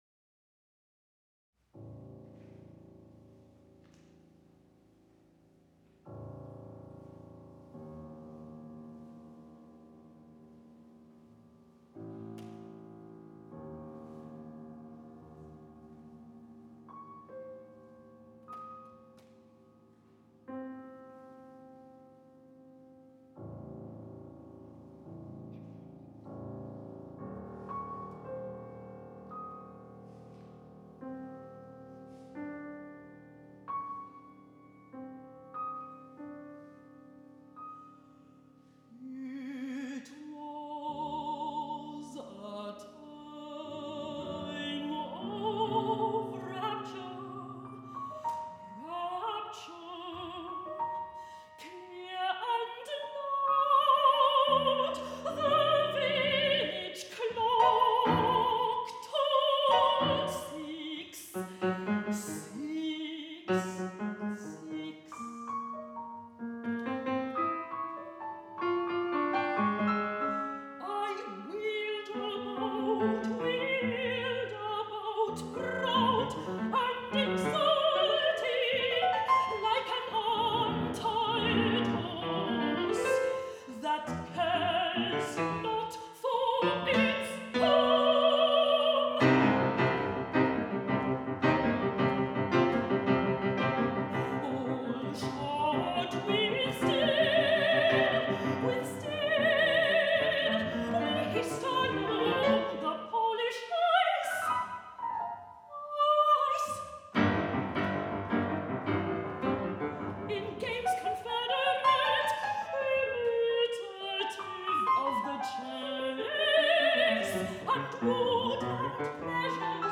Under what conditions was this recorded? Venue: St. Brendan’s Church WORLD PREMIERE